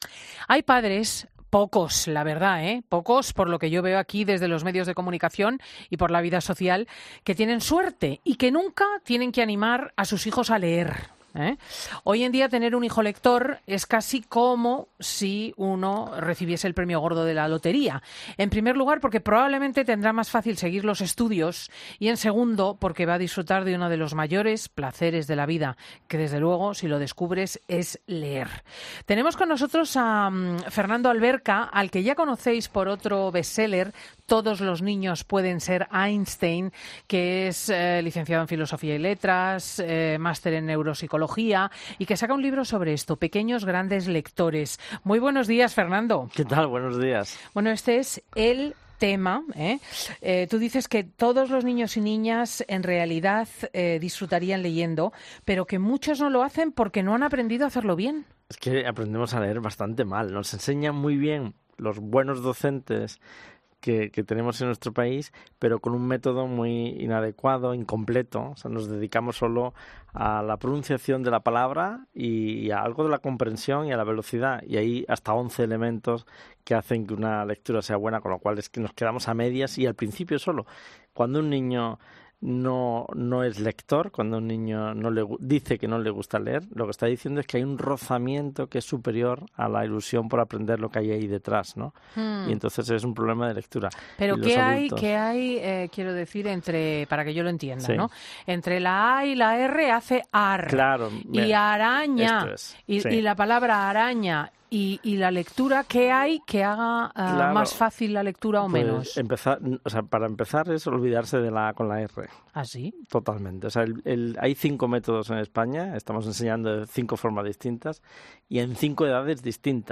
Pero para aquellos que se encuentran en otra autopista, en la que significa estar muy pendiente para animar a tu hijo en su hábito lector, les aconsejamos que escuchen la siguiente entrevista.